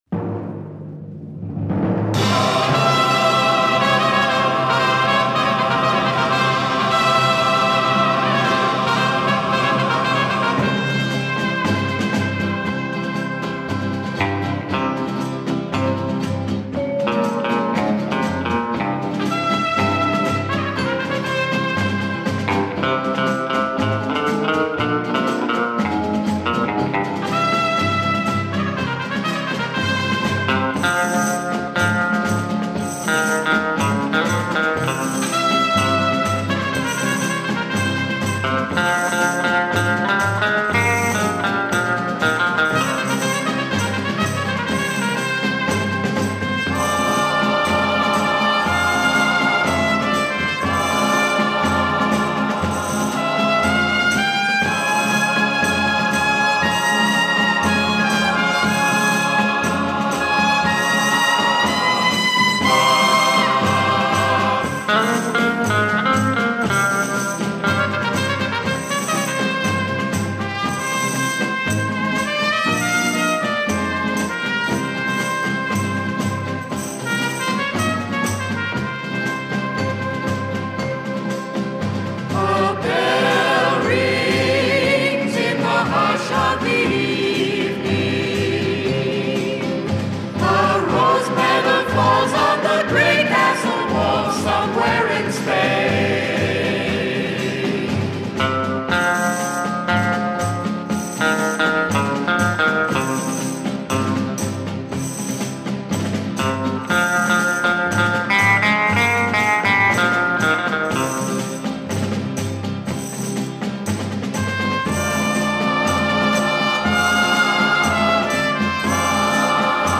Музыквльная специальность - труба